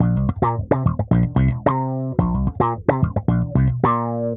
Index of /musicradar/dusty-funk-samples/Bass/110bpm